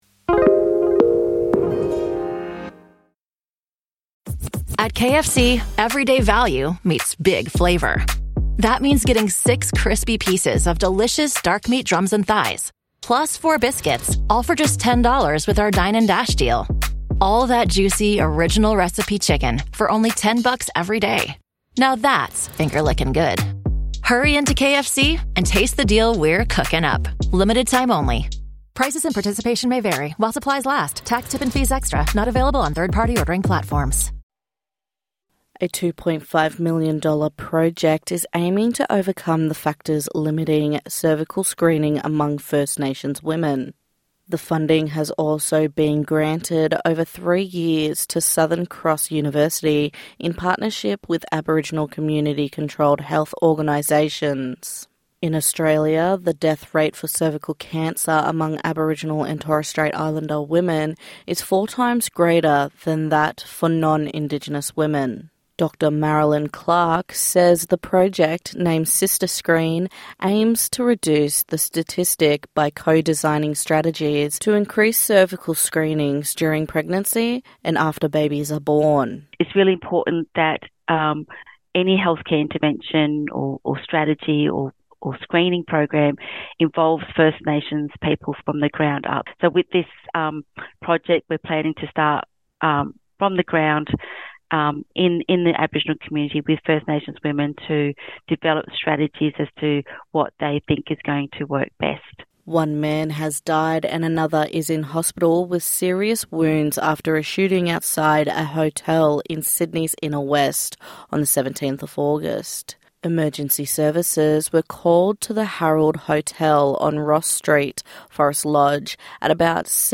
NITV Radio News - 18/08/2025